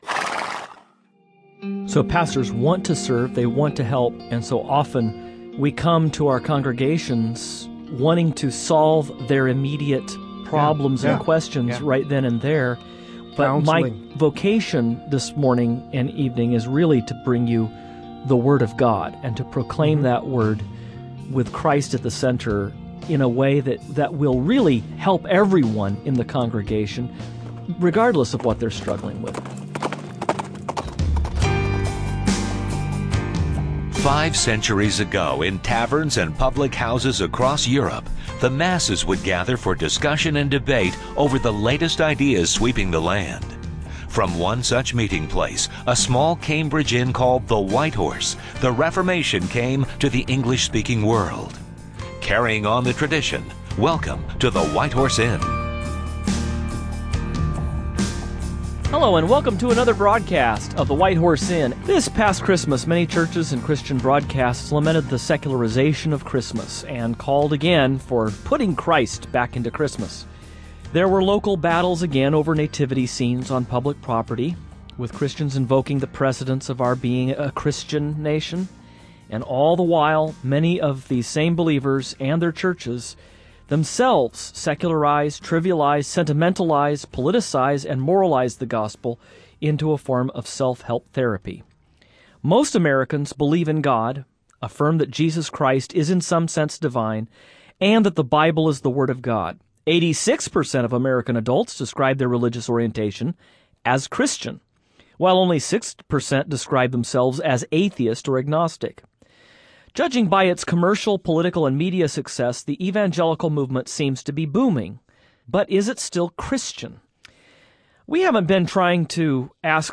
On this edition of the White Horse Inn the hosts offer some concluding thoughts about the year-long theme of "Christless Christianity." They also read some fan mail and listen to some of their own bloopers made over the past year and beyond.